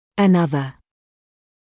[ə’nʌðə][э’назэ]ещё один; другой; новый